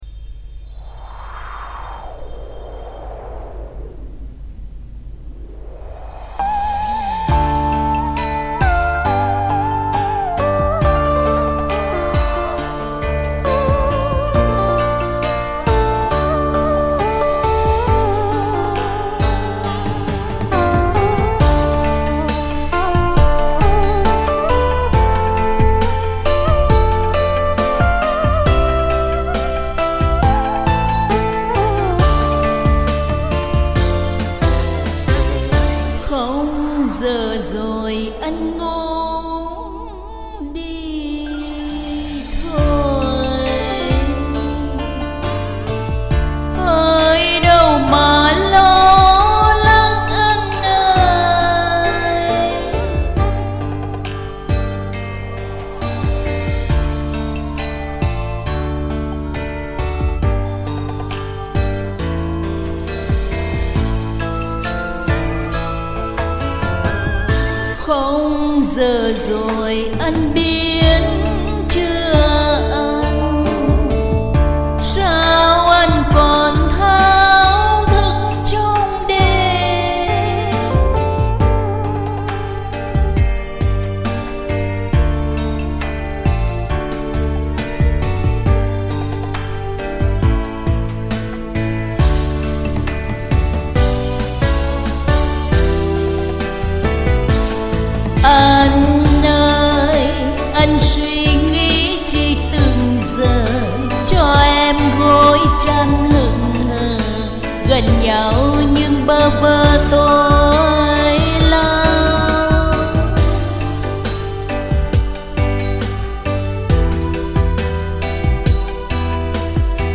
Beat tam ca